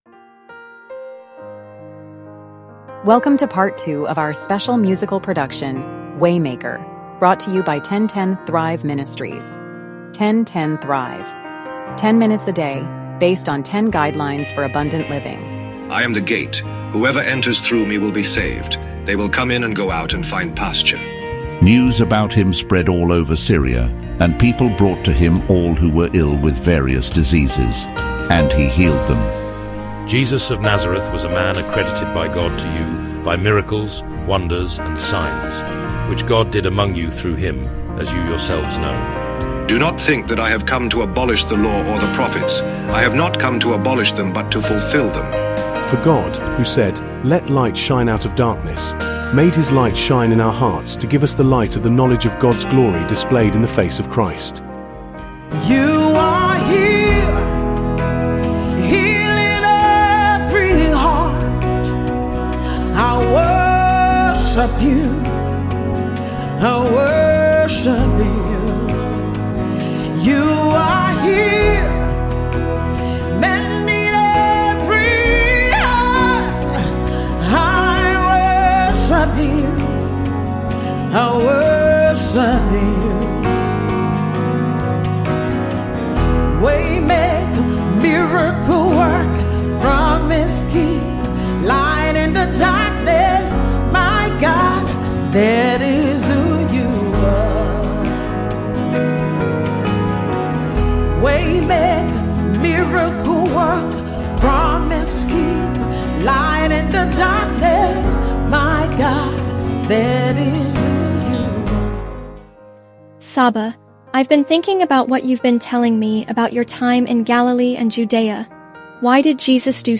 The episode opens with powerful scripture readings highlighting Jesus as the gate to salvation, the fulfillment of the Law, and the source of light in the darkness.